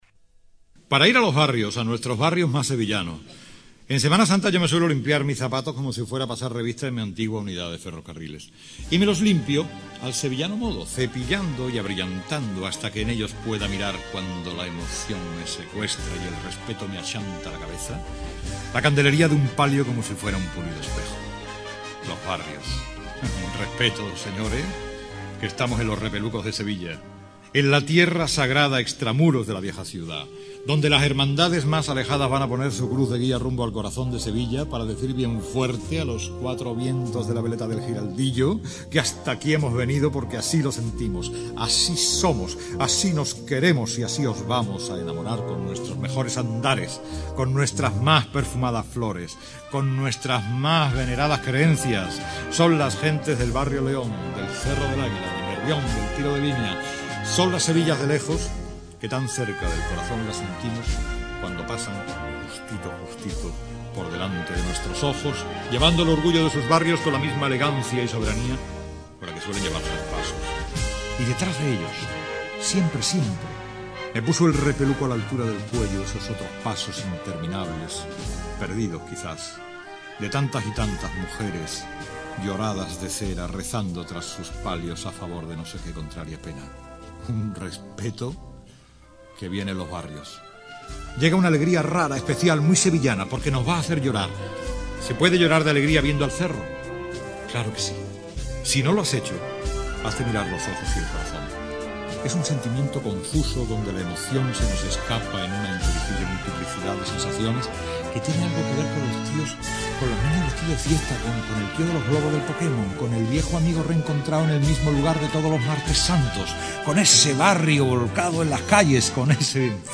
Temática: Cofrade